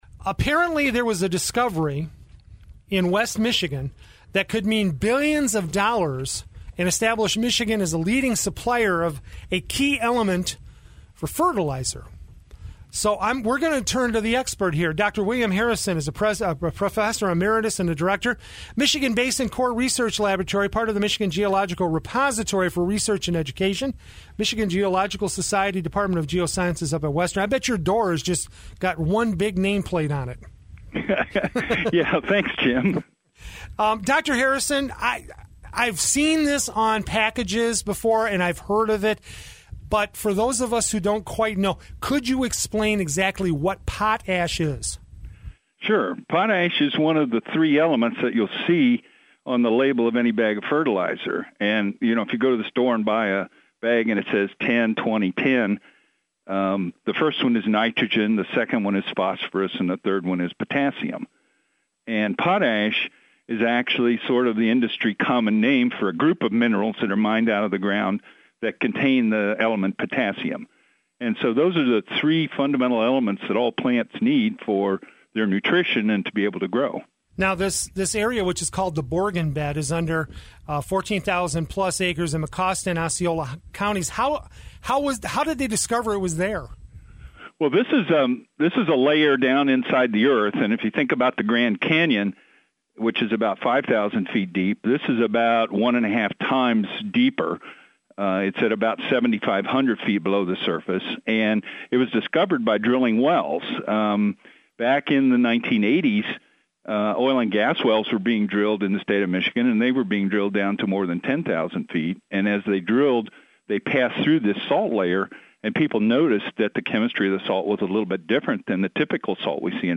Download File of WKZO Live Interview (audio/mpeg, 10.3 MB)